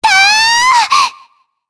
Shea-Vox_Attack4_jp.wav